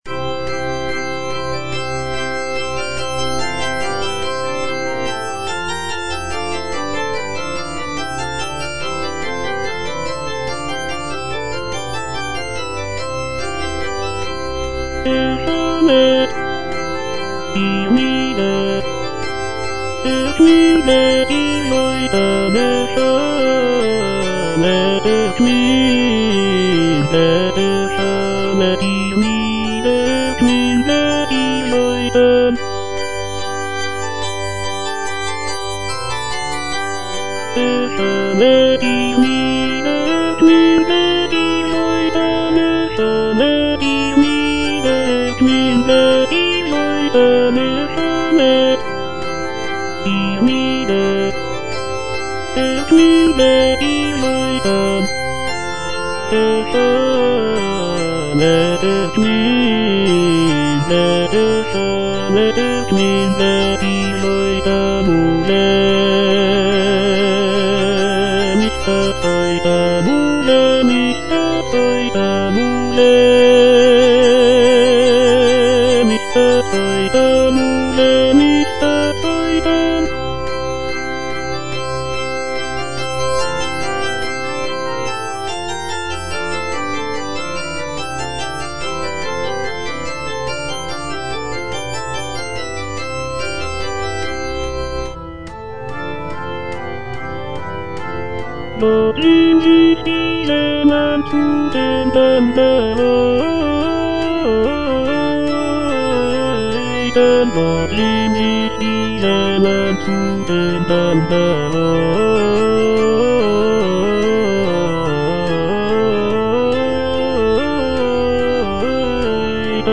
J.S. BACH - CANTATA "ERSCHALLET, IHR LIEDER" BWV172 (EDITION 2) Erschallet, ihr Lieder - Tenor (Voice with metronome) Ads stop: auto-stop
The text celebrates the coming of the Holy Spirit and the birth of the Christian Church. The music is characterized by its lively rhythms, rich harmonies, and intricate counterpoint.